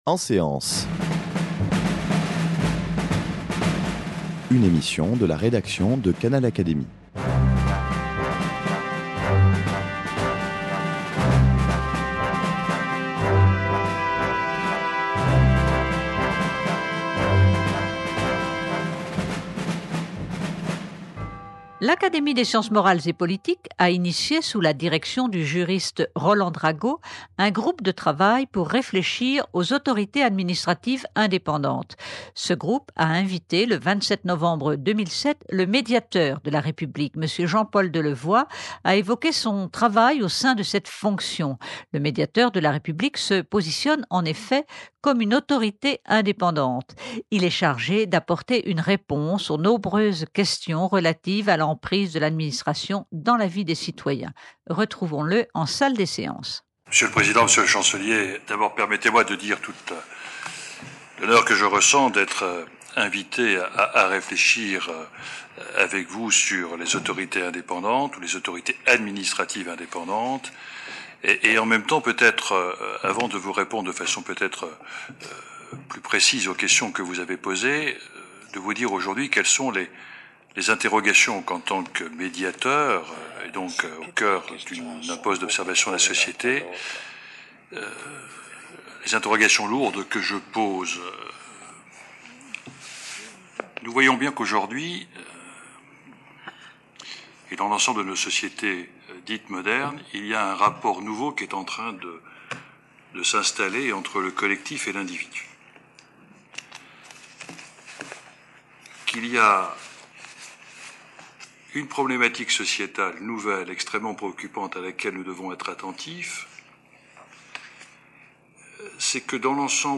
M. Jean-Paul Delevoye, Médiateur de la République, est venu présenter sa conception de cette fonction devant les académiciens de l’Académie des sciences morales et politiques, et tout particulièrement ceux du groupe de travail de réflexion sur les A.A.I. Autorités Administratives Indépendantes. Avec eux, il a évoqué les pouvoirs, les risques, les avantages de cet outil de lien entre les administrations et les citoyens.